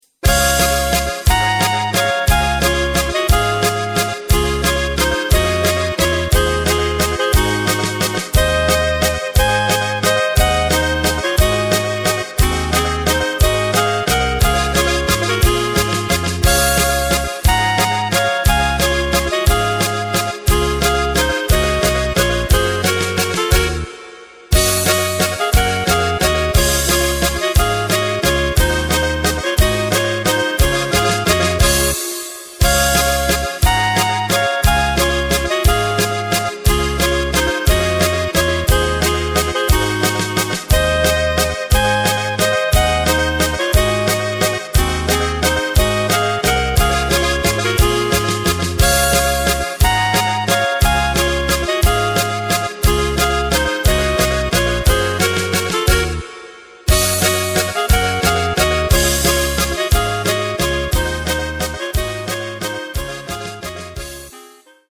Takt:          3/4
Tempo:         178.00
Tonart:            C
Schweizer Walzer-Ländler!
Playback mp3 Mit Drums